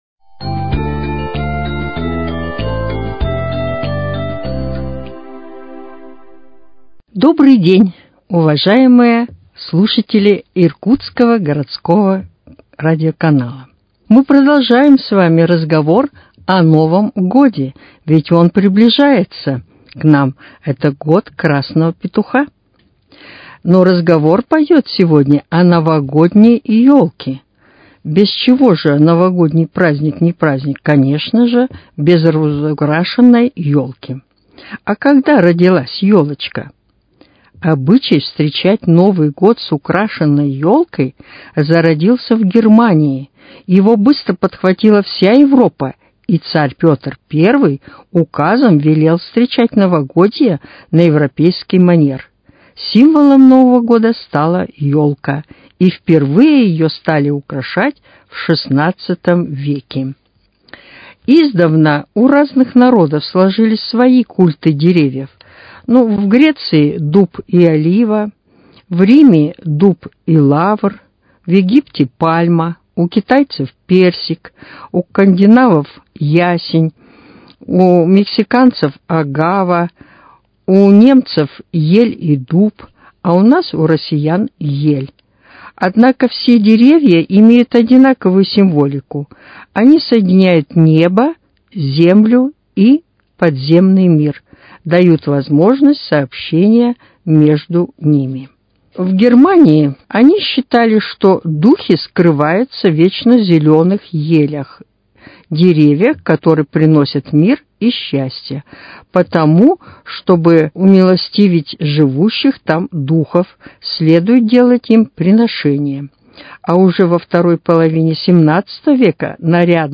Сегодня в день его 30-летия, мы вместе послушаем голоса наших уважаемых нештатных авторов, ушедших в мир горний, которые многие годы трудились на общественных началах, просвещая и созидая, и свет их души, р